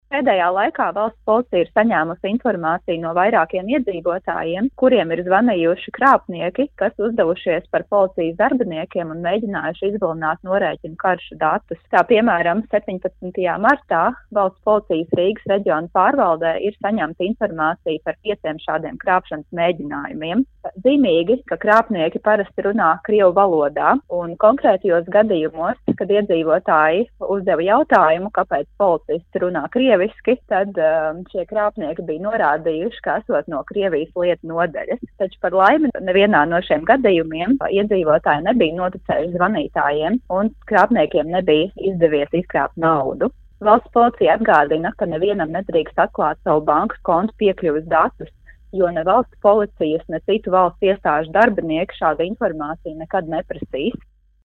RADIO SKONTO Ziņās par jauna veida telefonkrāpniekiem